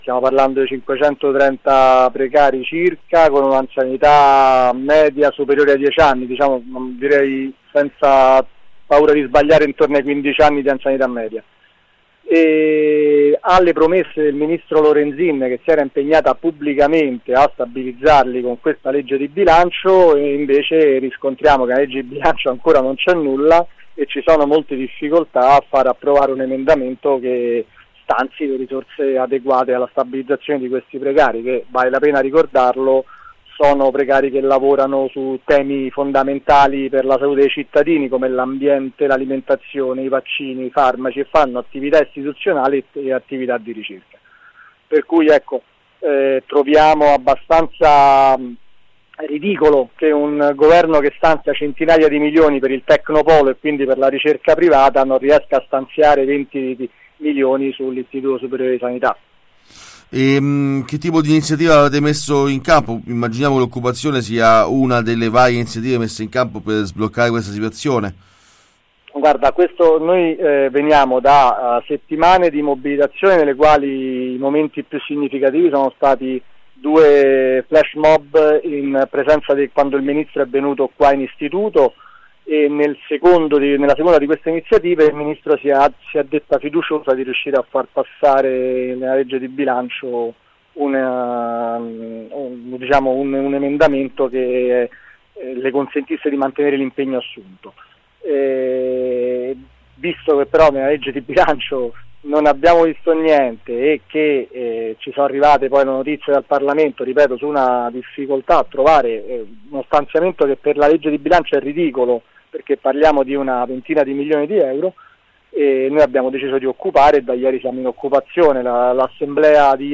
Roma-Occupato l’Istituto Superiore di Sanità. Corrispondenza